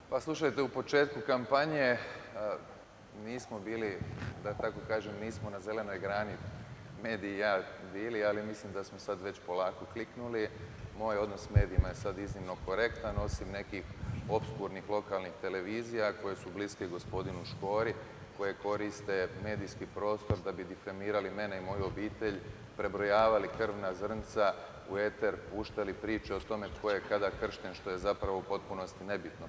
Okršaj u zagrebačkoj Esplanadi otkrio je kako izgleda kada se na jednom mjestu okupi deset političkih oponenata. Izdvojili smo zanimljive odgovore kandidata iz višesatnog sučeljavanja. HDZ-ov kandidat Davor Filipović odgovarao je na pitanje o odnosu s medijima. Poručio je kako je njegova izjava o izgradnji tvornice cjepiva pogrešno interpretirana, ali da je na kraju ipak uspio pronaći zajednički jezik s medijima.